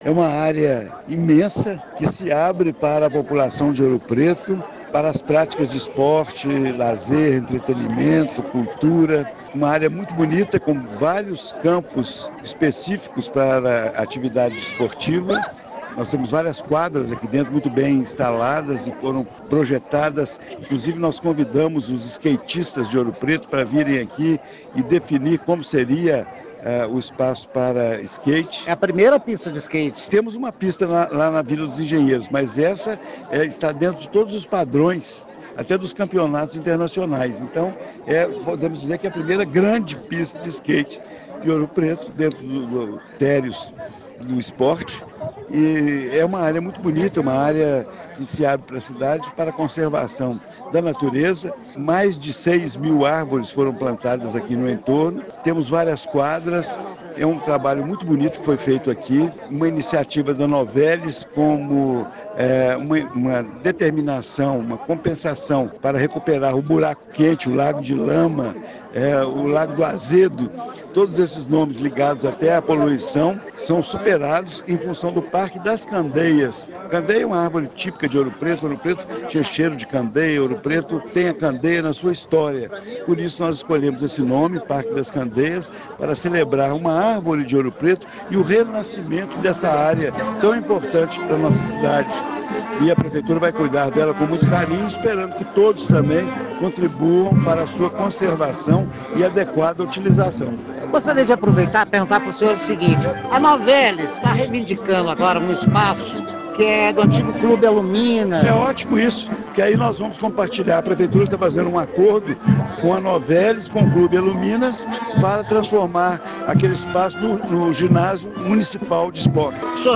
ENTREVISTA PREFEITO DE OURO PRETO – Na inauguração do complexo, o prefeito Angelo Oswaldo comentou os benefícios que a extensa área de recreação e contemplação irá trazer para a comunidade e antecipou que já existe um diálogo com a Novelis sobre o conjunto de imóveis que compõem os espaços do Clube Aluminas, em Saramenha.